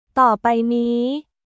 トー・パイ・ニー